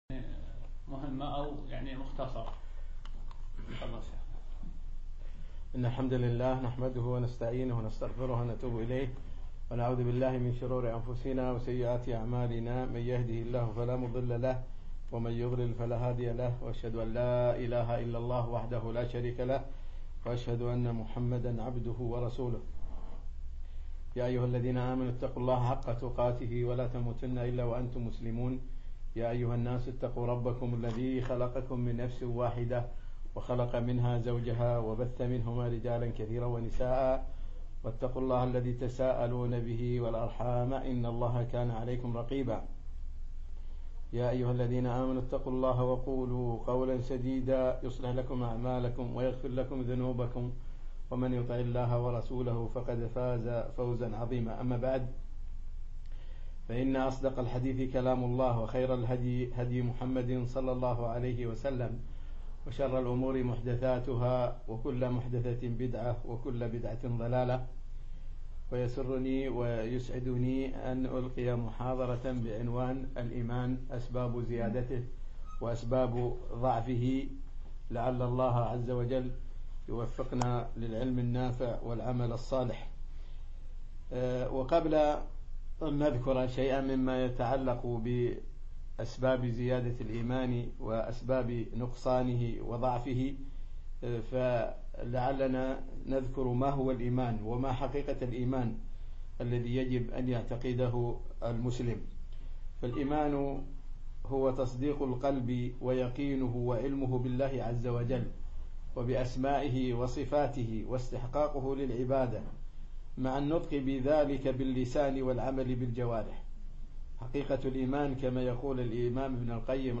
يوم الخميس 17 شعبان 1436 الموافق 4 6 2015 بمركز القصر نساء مسائي
أسباب زيادة الإيمان وضعفه - محاضرة